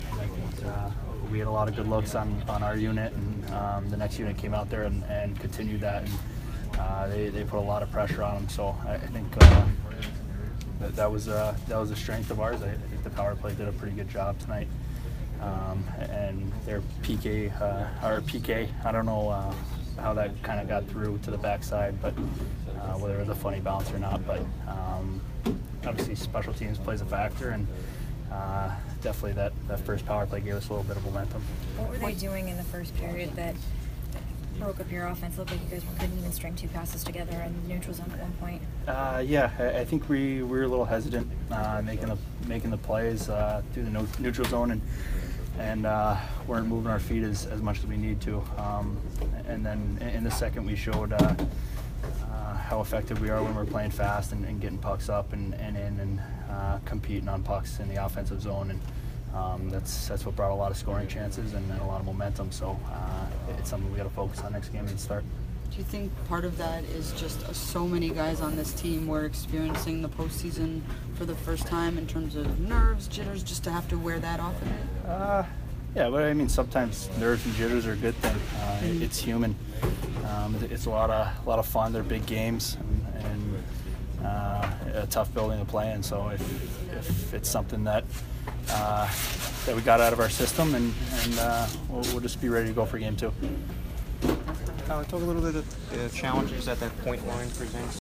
Kyle Palmieri post-game 4/12